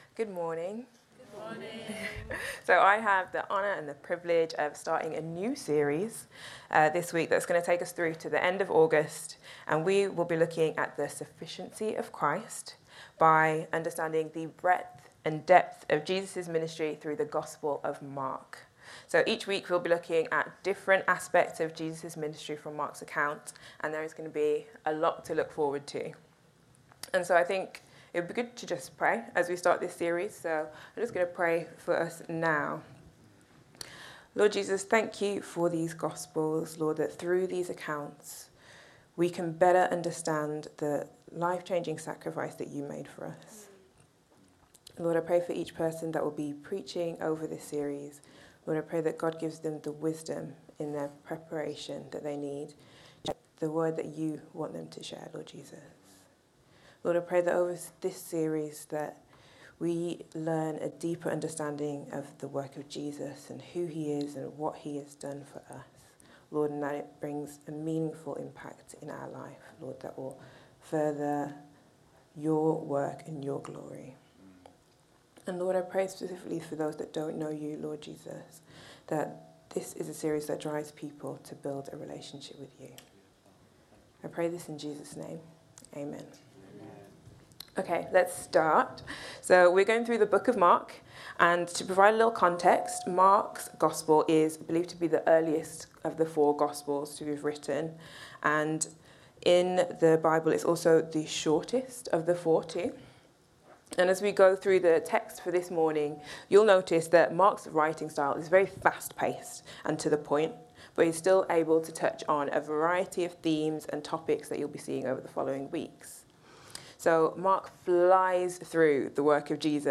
Download Paving The Way | Sermons at Trinity Church